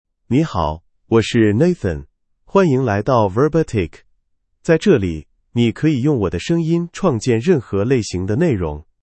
Nathan — Male Chinese (Mandarin, Simplified) AI Voice | TTS, Voice Cloning & Video | Verbatik AI
Nathan is a male AI voice for Chinese (Mandarin, Simplified).
Voice sample
Listen to Nathan's male Chinese voice.
Male